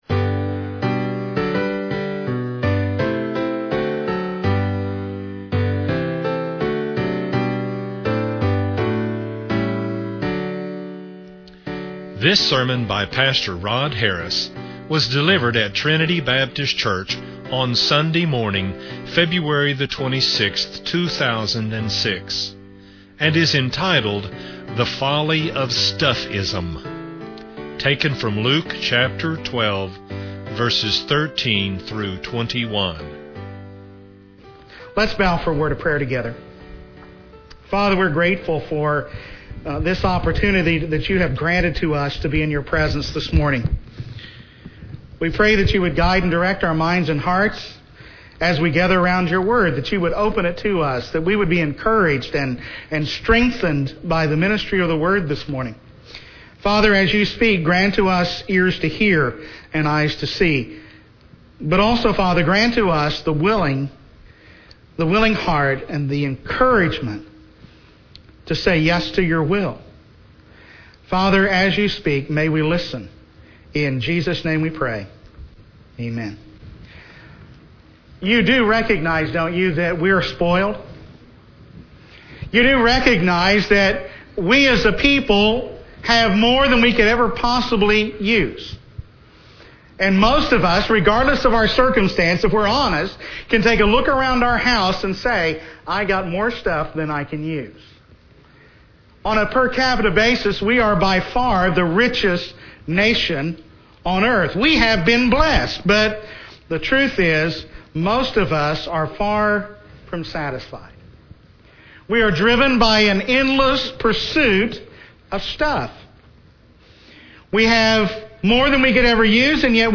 This is an exposition of Luke 12:13-21.